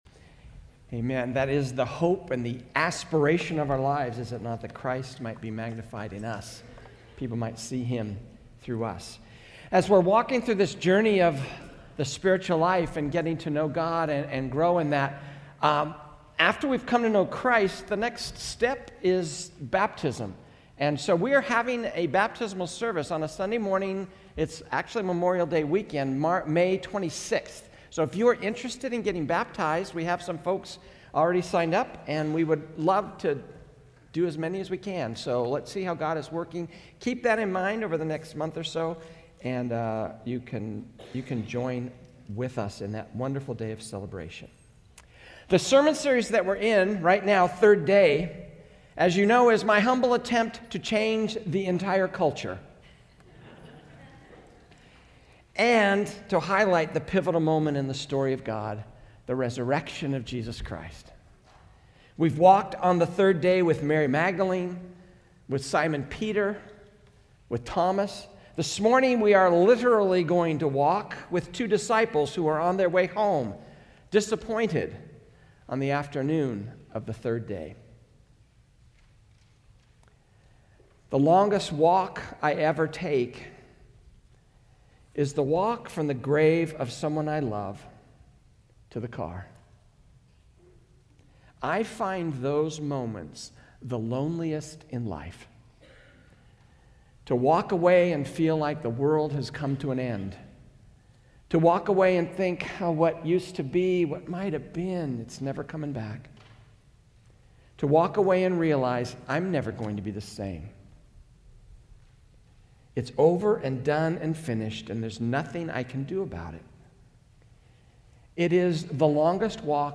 A message from the series "Third Day."